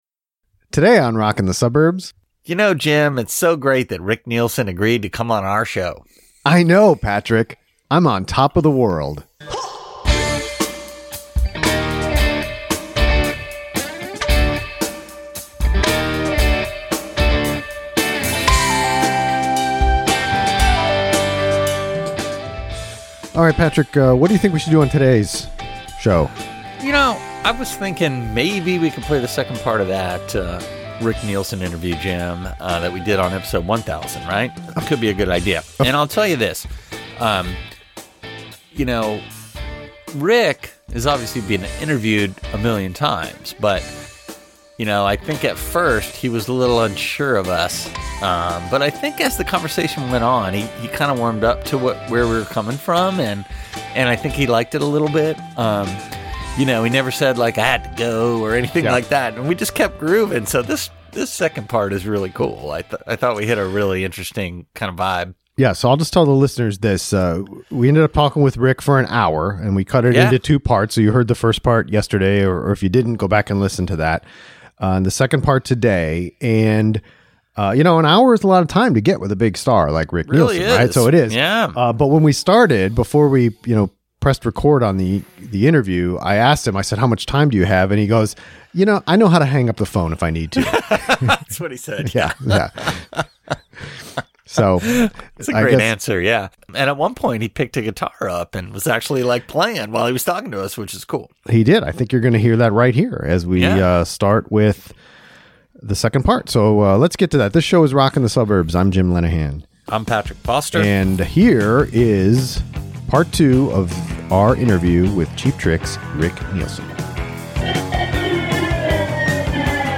Rick Nielsen - Interview, Part 2
Part 2 of our very special interview with Rick Nielsen. He discusses Cheap Trick's relation to the '70s punk scene, Kiss records, his guitar collection, the song he wishes were more popular and his remembrances of John Lennon.